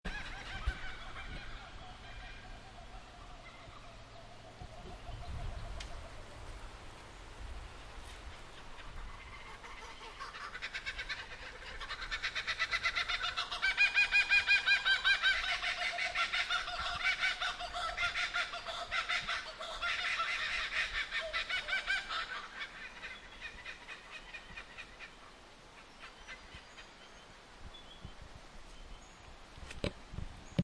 Kookaburras And Other Birds Of Dorrigo Rain Forest Nsw Aust 74961 Mp 3